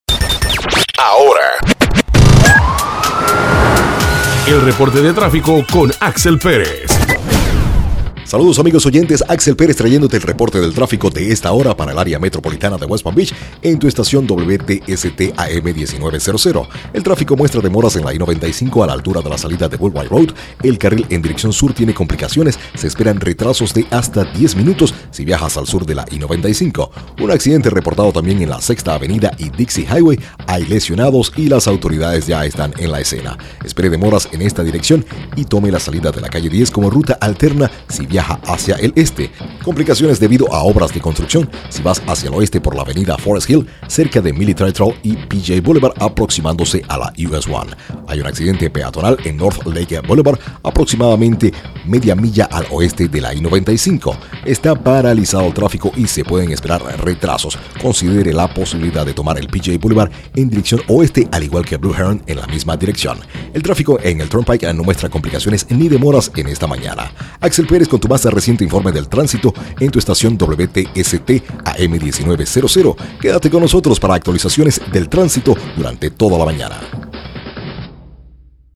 Please listen to any of the included Demo’s. Our reporter’s have a wide range or accents and dialects and can provide traffic reports for nearly any market in the county.
Male Voice #1 – Spanish